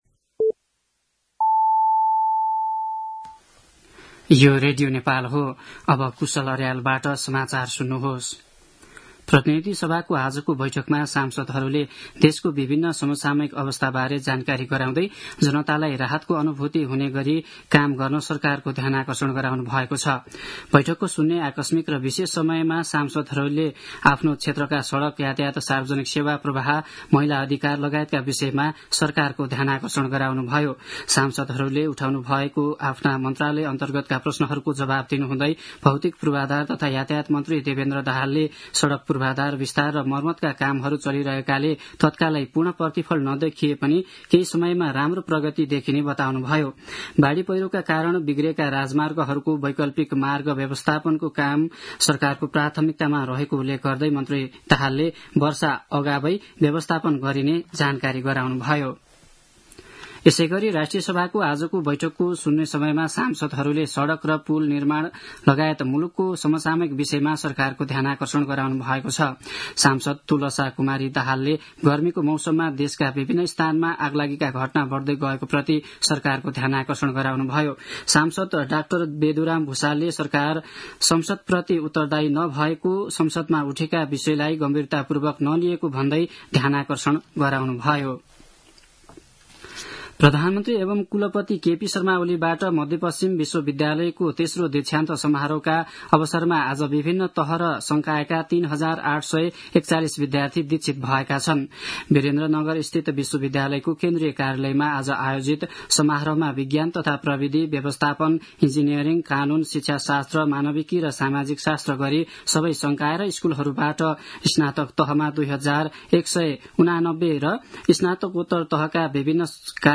दिउँसो ४ बजेको नेपाली समाचार : २४ फागुन , २०८१
4pm-news.mp3